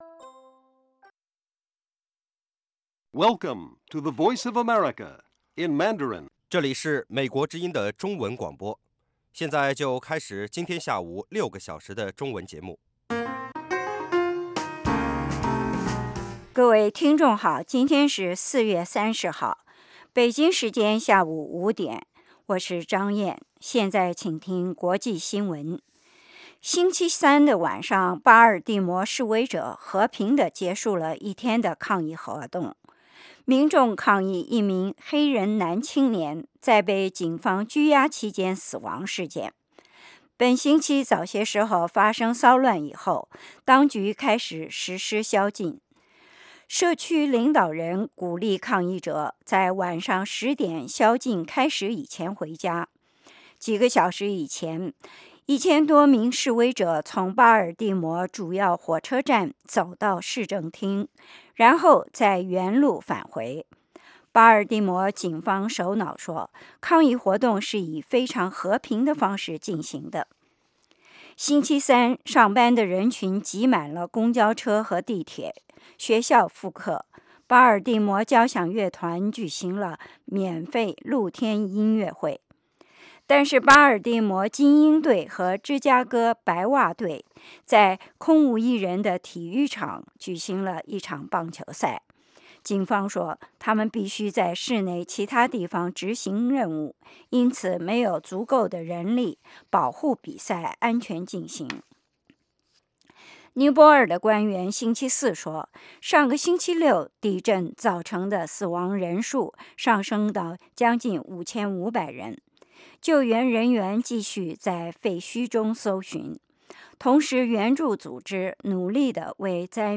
北京时间下午5-6点广播节目。 内容包括国际新闻和美语训练班（学个词，美国习惯用语，美语怎么说，英语三级跳，礼节美语以及体育美语）